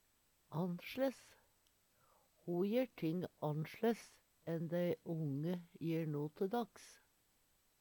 Sjå òg no te daks (Veggli) Høyr på uttala Ordklasse: Adverb Attende til søk